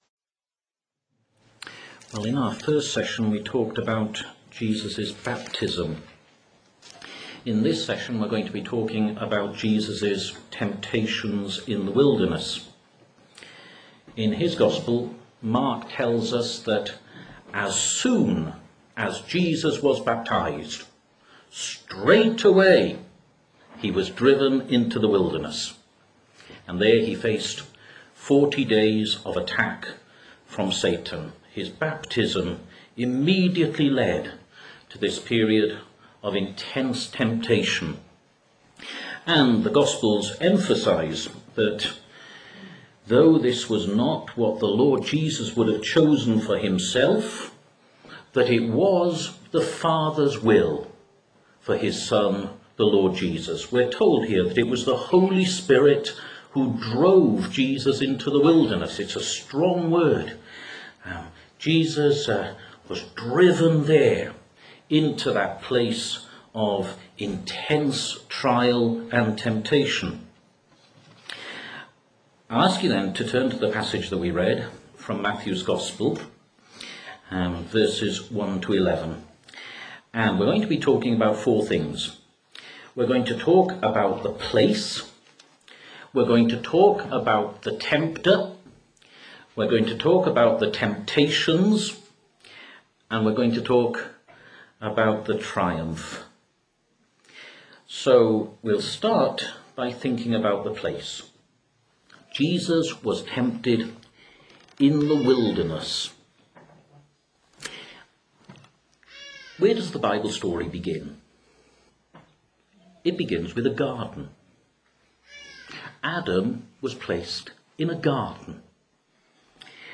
2010 Questions & Answers